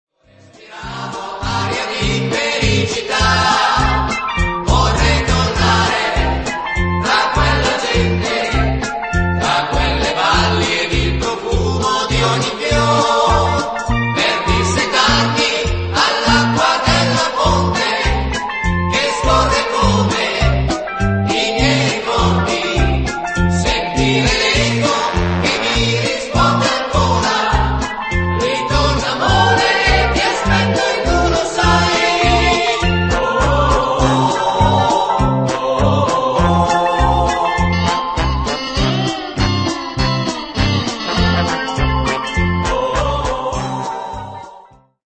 fox-trot